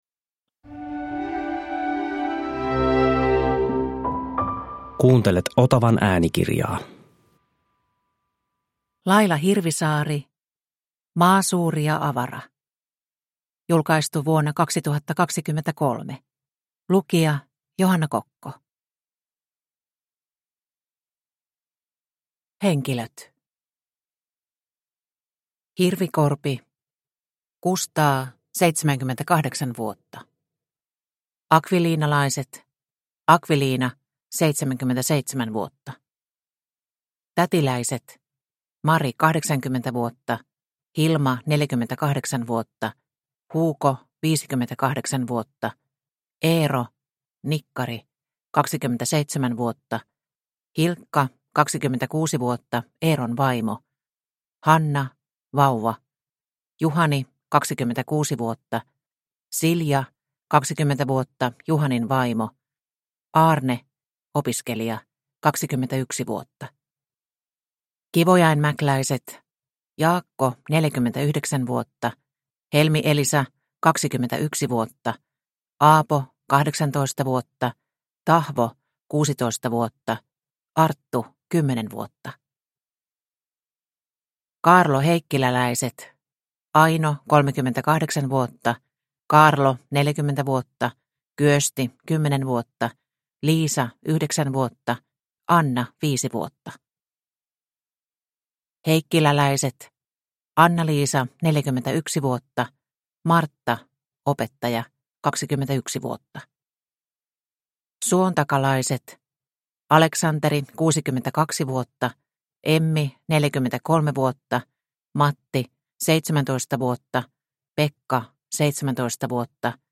Maa suuri ja avara – Ljudbok – Laddas ner